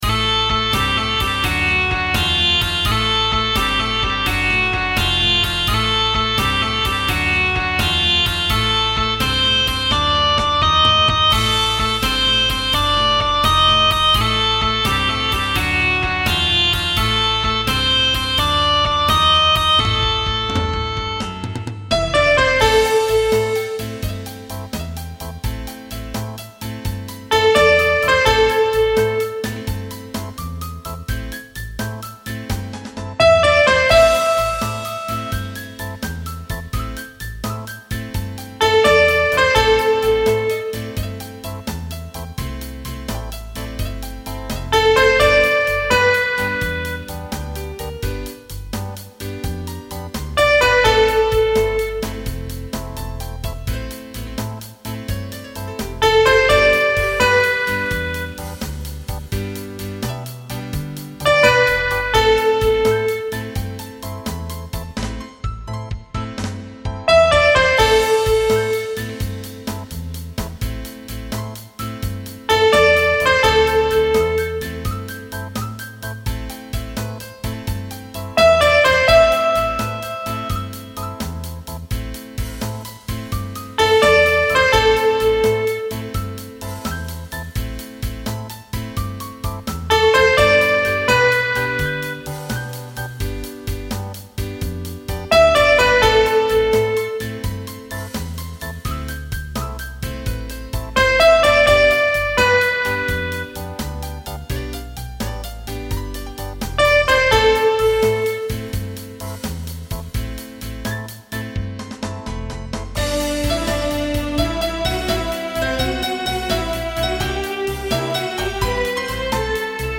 "blues"
Recording from MIDI